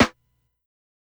SNARE_SOLAR.wav